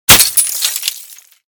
/ gamedata / sounds / material / bullet / collide / glas01hl.ogg 24 KiB (Stored with Git LFS) Raw History
glas01hl.ogg